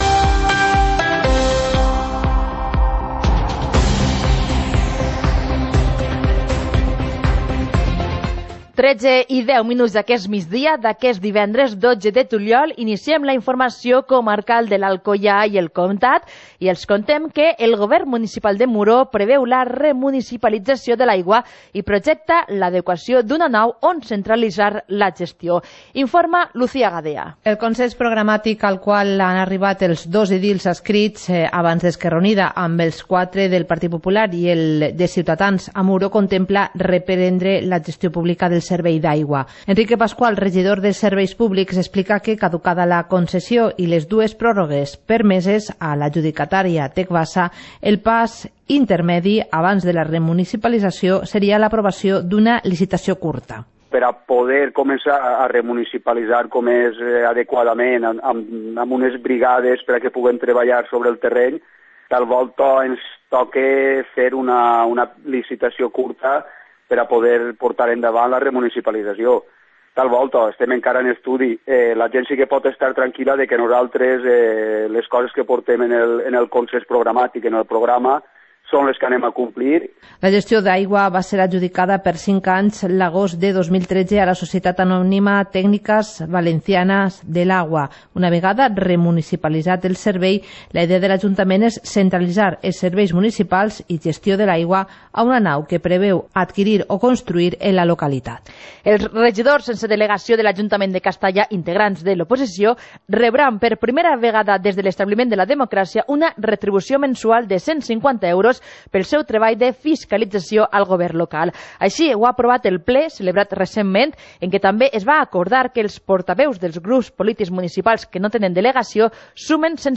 Informativo comarcal - viernes, 12 de julio de 2019